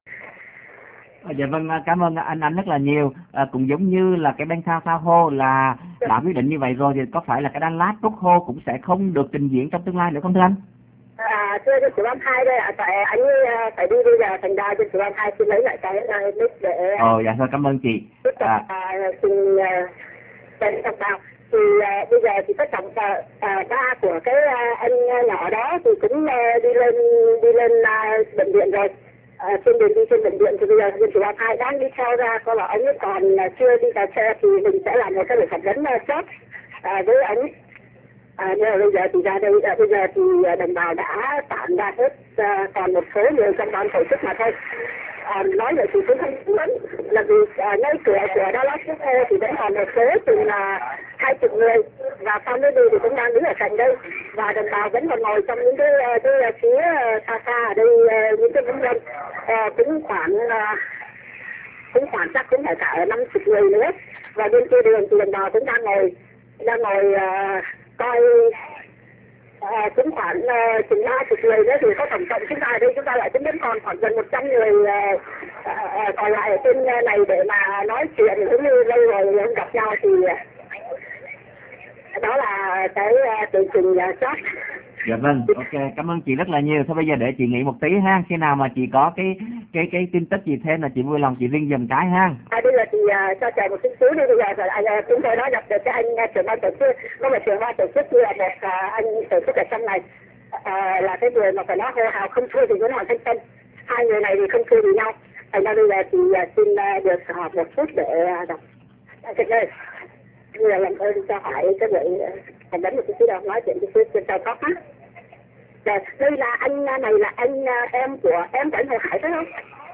Xin qu� vị chờ trong gi�y l�t sẽ nghe được �m thanh từ cuộc biểu t�nh (phần 3)